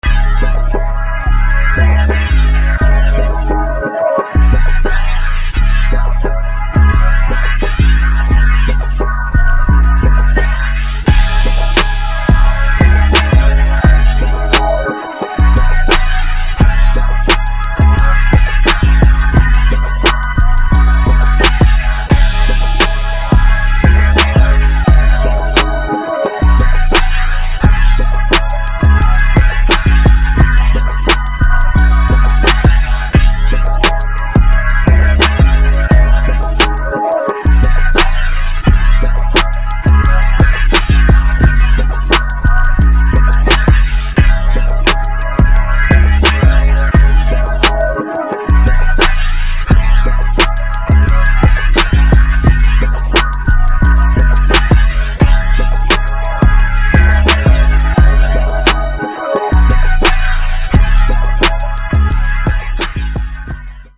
West Coast Hip Hop Beat Sync Licensing